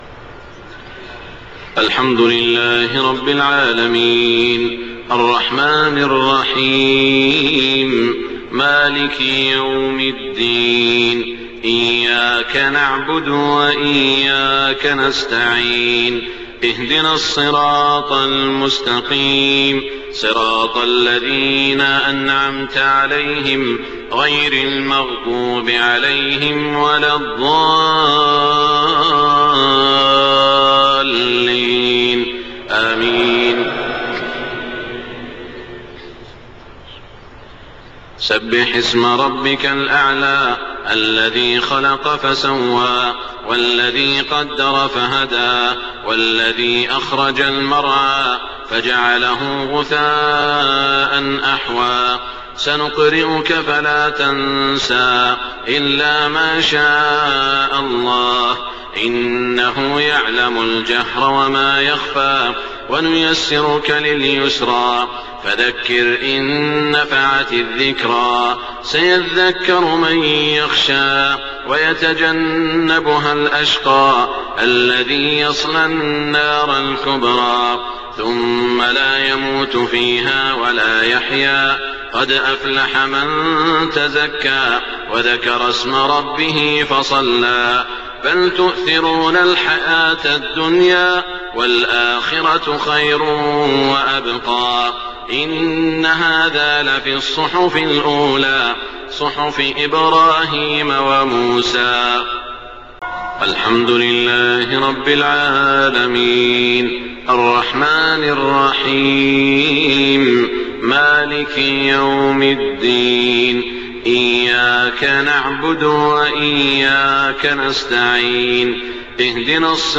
صلاة الجمعة 1-5-1428هـ سورتي الأعلى و الغاشية > 1428 🕋 > الفروض - تلاوات الحرمين